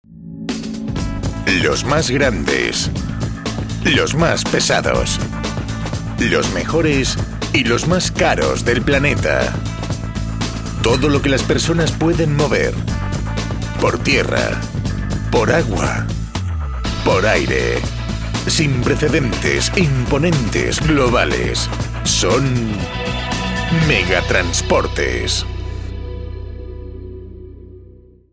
kastilisch
Sprechprobe: Industrie (Muttersprache):
Voice talent specialized in Dubbing Movies, Documentaries & Cartoons